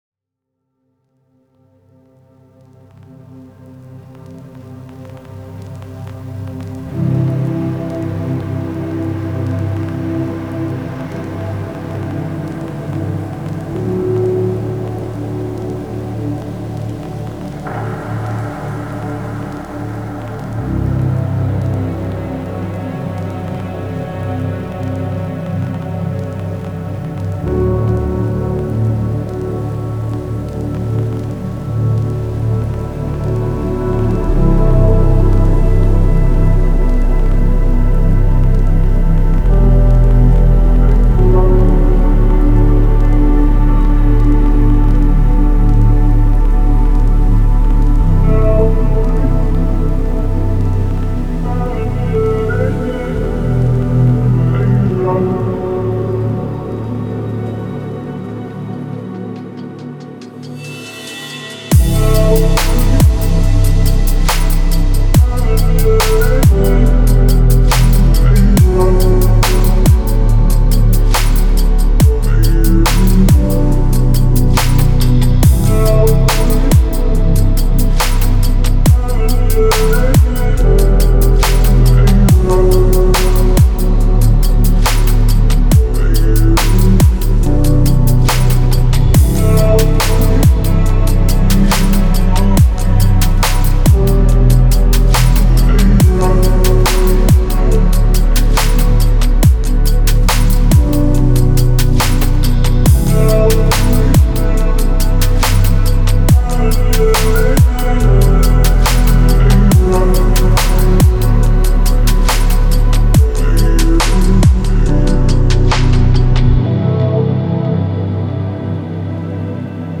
это трек в жанре поп с элементами инди